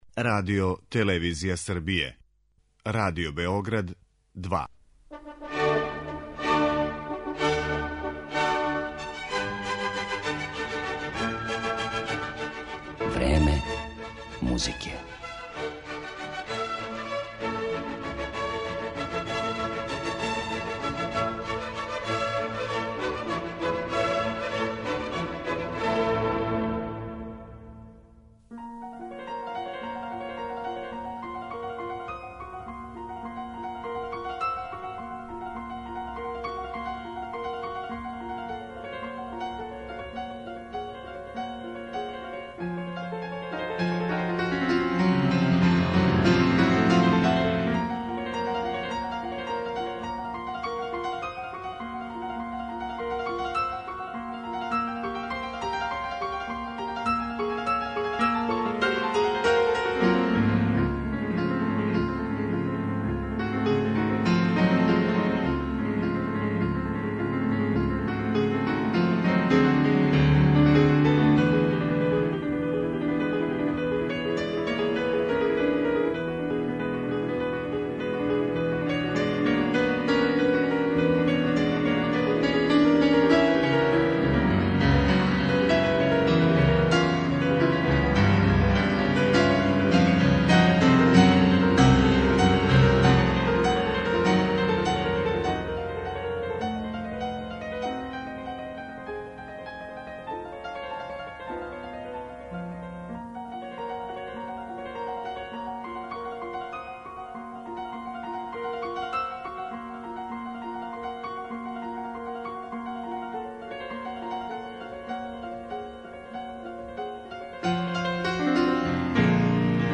пијанисткиње
У њиховој ћете интерпретацији слушати дела Равела, Сен-Санса, Бабића и Рамоа.